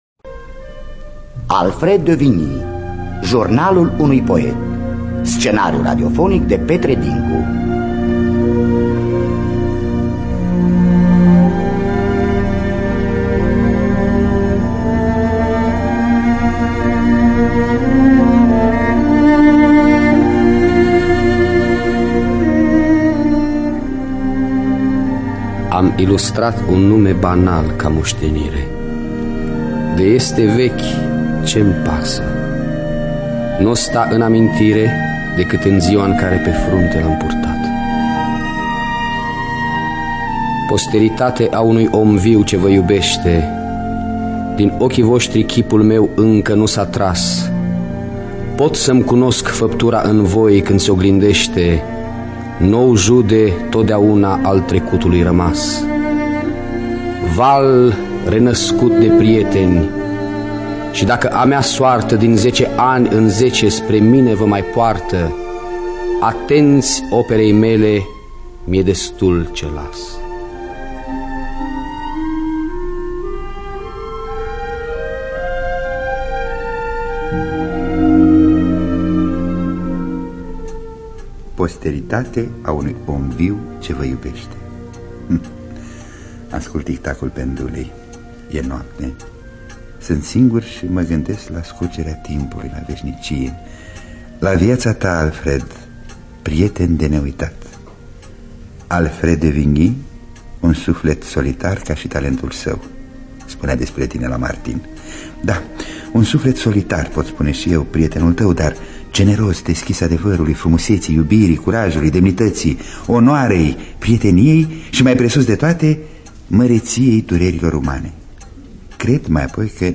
Biografii, Memorii: Alfred de Vigny – Jurnalul Unui Poet (1982) – Teatru Radiofonic Online